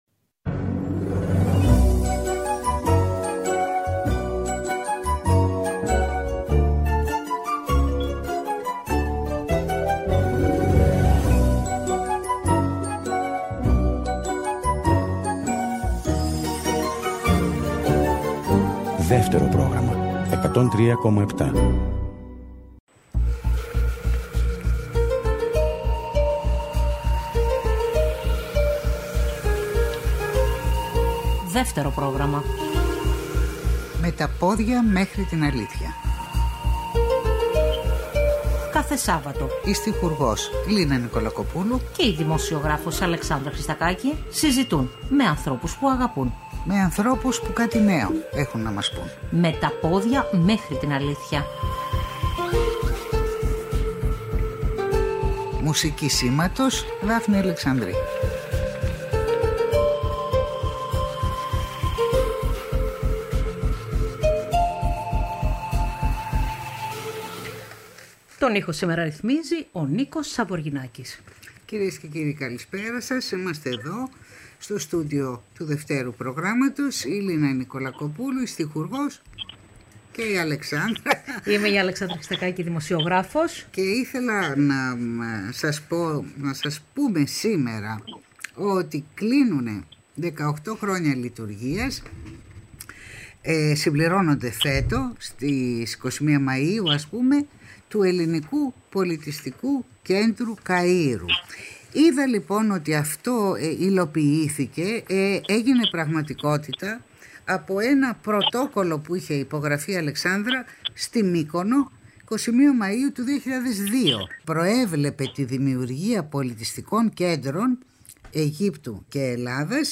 Συζητούμε με Έλληνες και Αιγύπτιους λογοτέχνες, δασκάλους και φοιτητές, που αγαπούν την ελληνική γλώσσα και τον ελληνικό πολιτισμό.